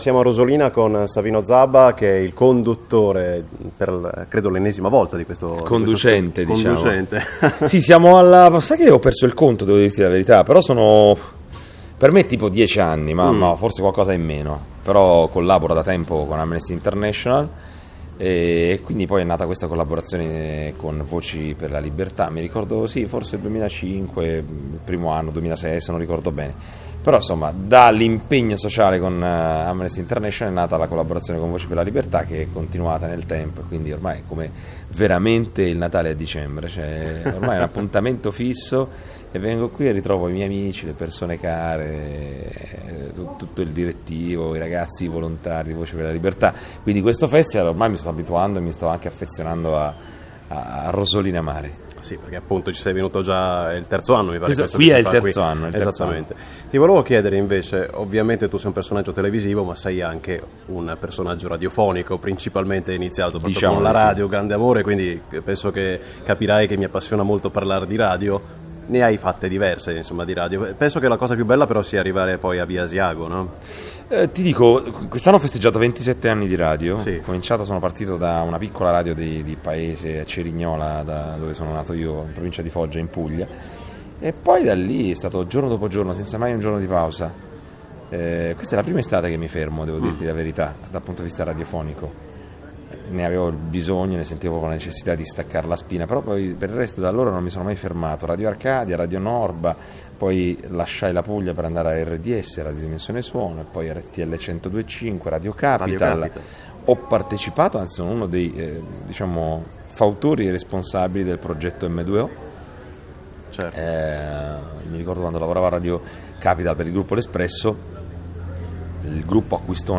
Le interviste di Voci per la Libertà
In attesa di uno Speciale VxL '14 che prepareremo per il mese di agosto, abbiamo deciso di rendere disponibili per l'ascolto tutte le interviste che abbiamo realizzato il 18-19 e 20 luglio a Rosolina Mare durante le serate della XVII edizione di Voci per la Libertà - Una Canzone per Amnesty.